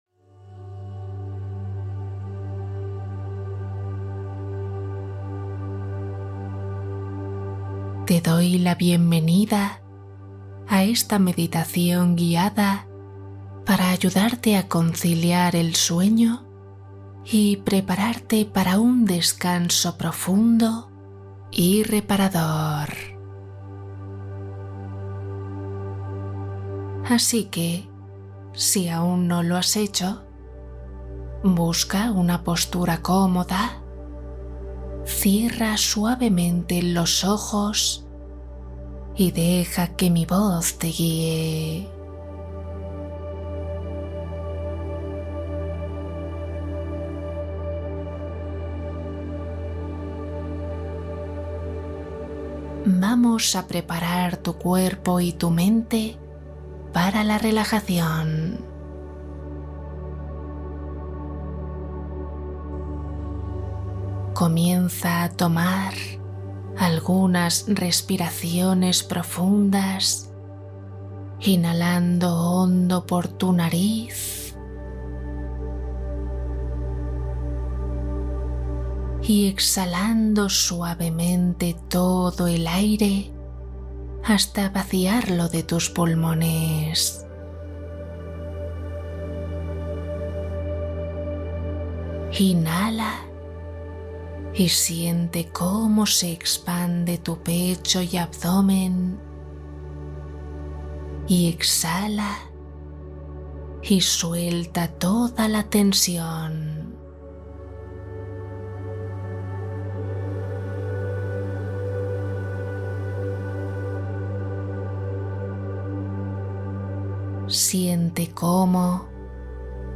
Cuento + Meditación Para calmar ansiedad, estrés y dormir rápido